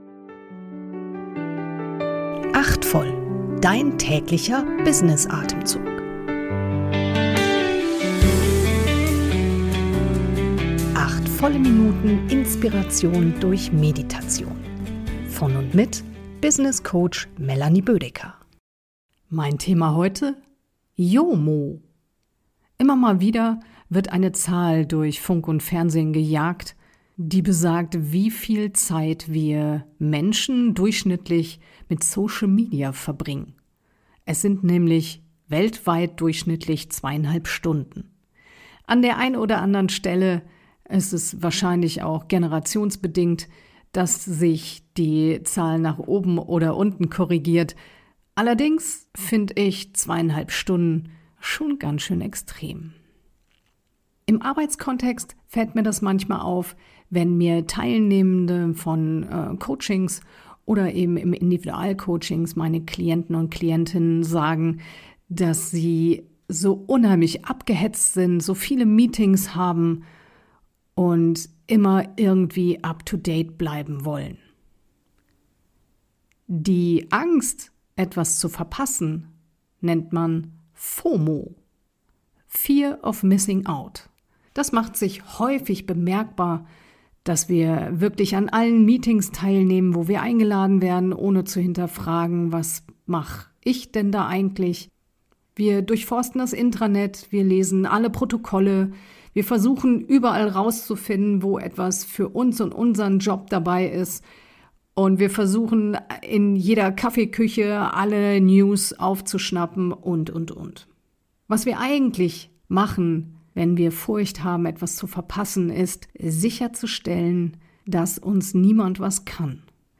geleitete Kurz-Meditation.